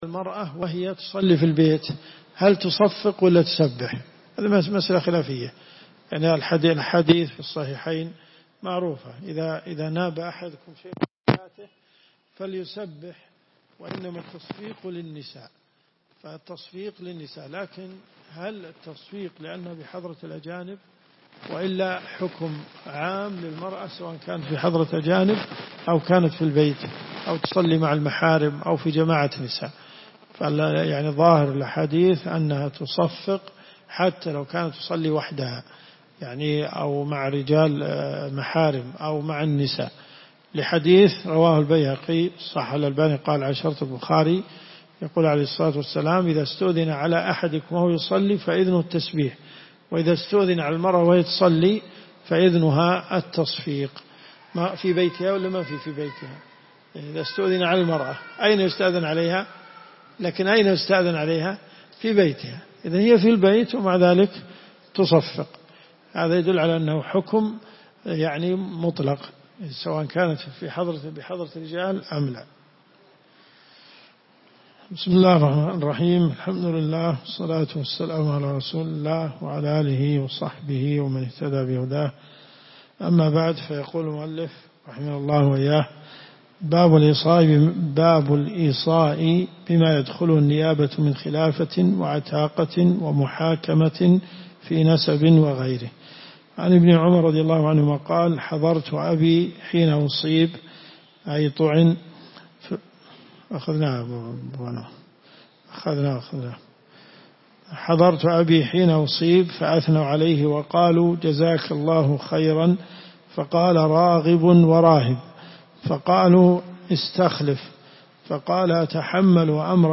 الرئيسية الكتب المسموعة [ قسم أحاديث في الفقه ] > المنتقى من أخبار المصطفى .